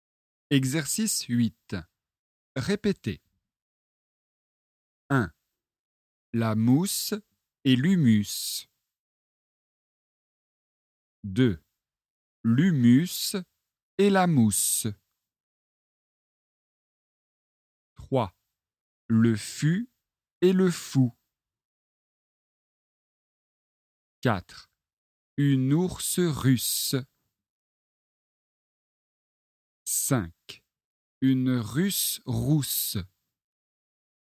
Leçon de phonétique, niveau débutant (A1).
Exercice 8 : répétez.